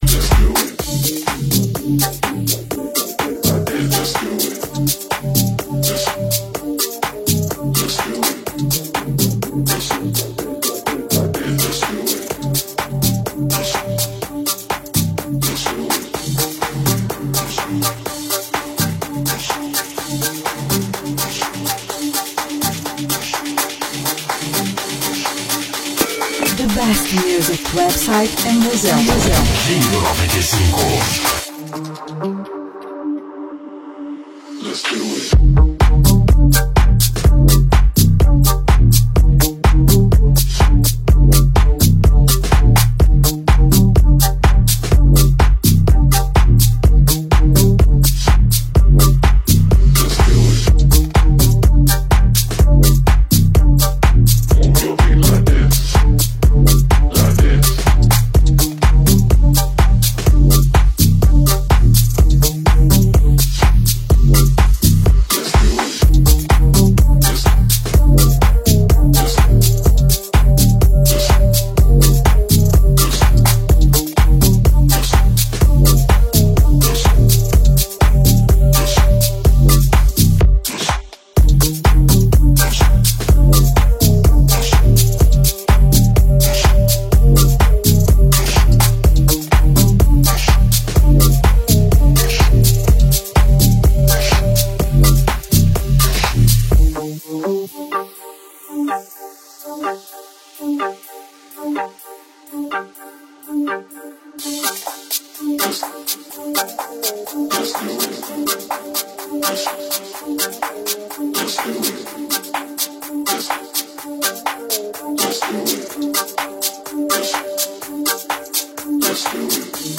Vem que o som vai ser pancada